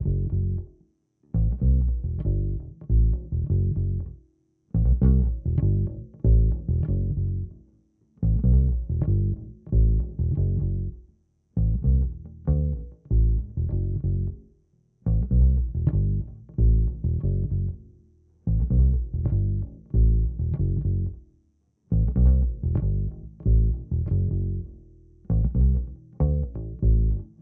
A Boombap lofi vibe sample pack with a jazzy dark ambient aura that make this collection of samples perfect to improvise over and get deep, complex and introspective tracks